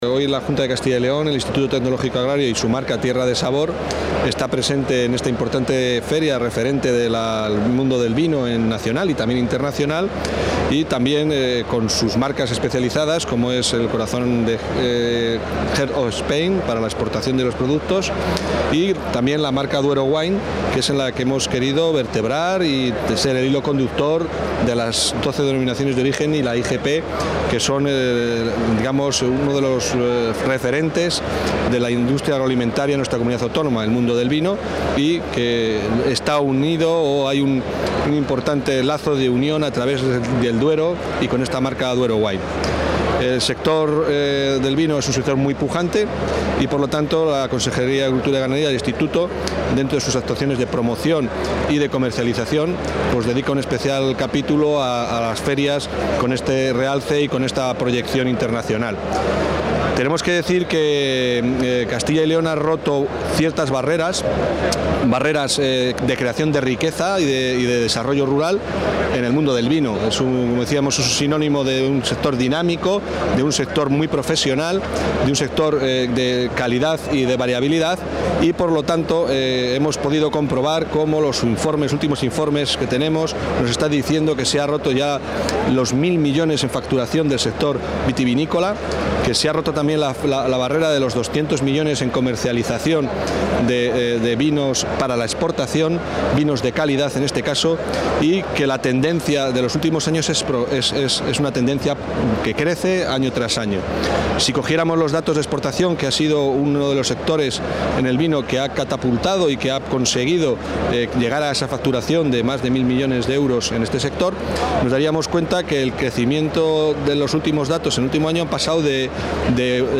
Declaraciones del viceconsejero de Desarrollo Rural y director del Itacyl.